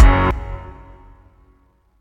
Gunit Synth18.wav